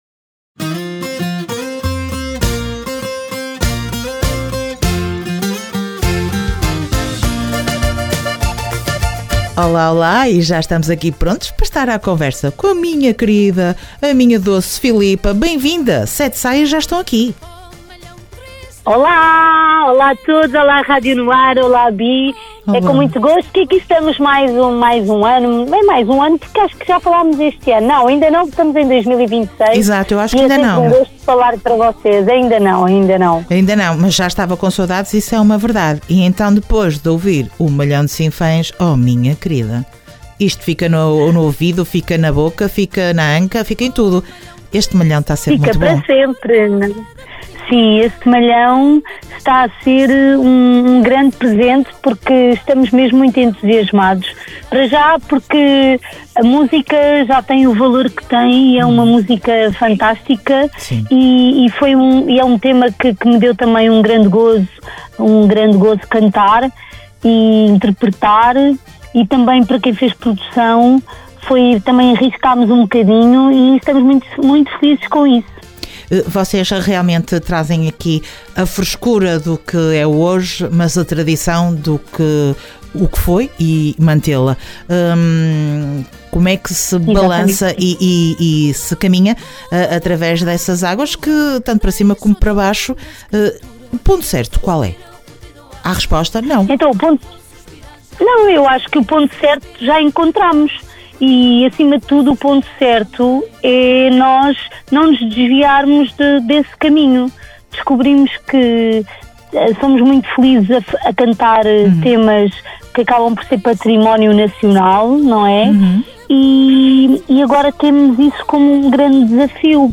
Entrevista ás 7 saias dia 19 de Abril